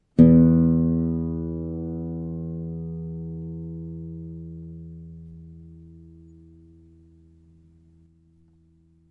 Звуки гитары, струн
Грустный звук тюнинг на гитаре